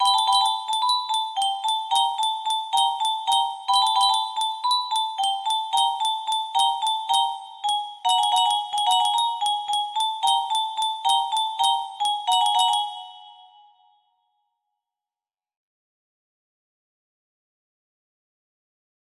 Repetition - unfinished music box melody